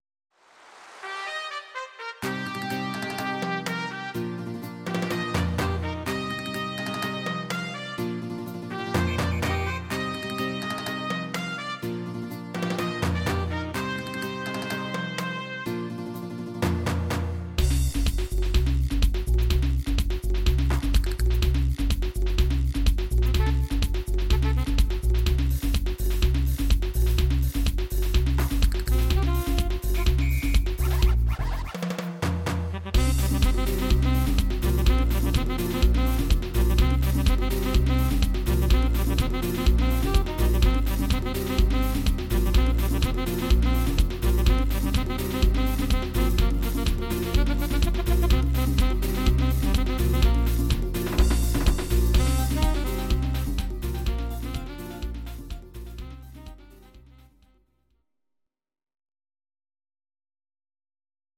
These are MP3 versions of our MIDI file catalogue.
Please note: no vocals and no karaoke included.
Your-Mix: Pop (21615)